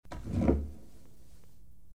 Звук молотка судьи
Звук, когда берут молоток рукой.